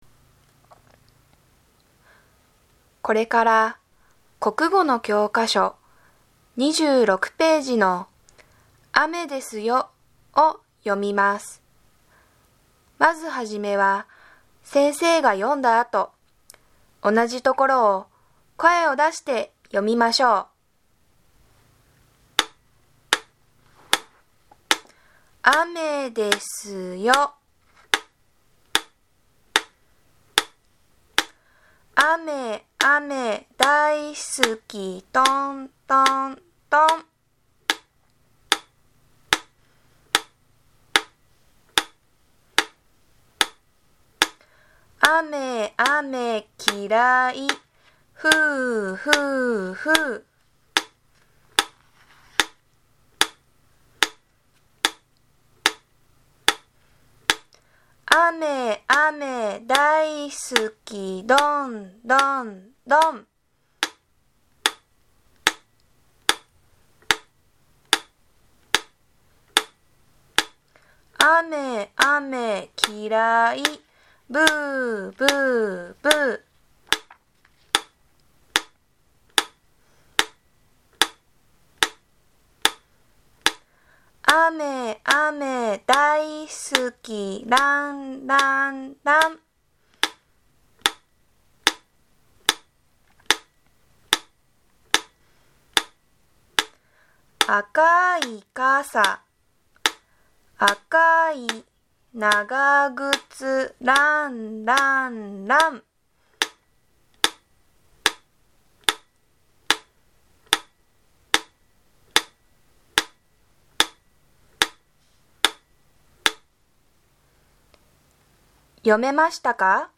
１年生の音読練習用の音声（パート２）ができました。